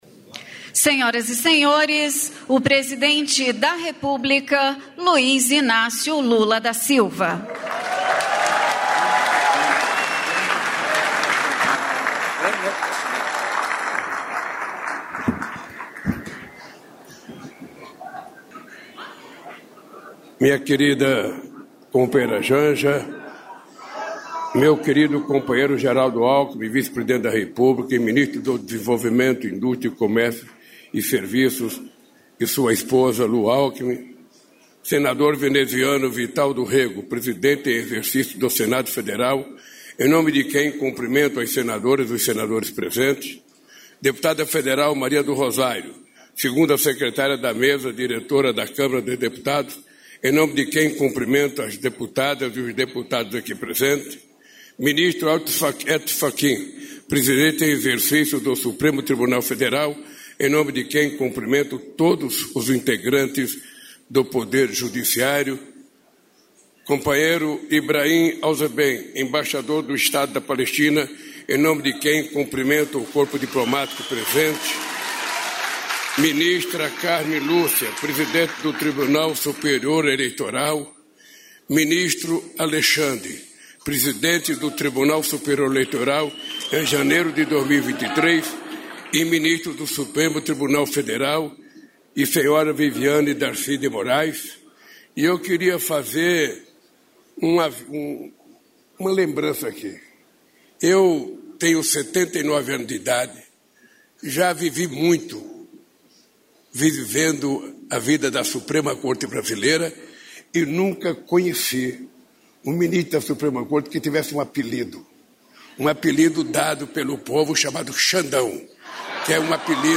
Íntegra do discurso do presidente Luiz Inácio Lula da Silva, durante a cerimônia de inauguração da fábrica de celulose da Suzano, em Ribas do Rio Pardo (MS), nesta quinta-feira (5).